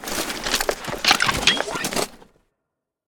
combat / weapons / rocket / draw.ogg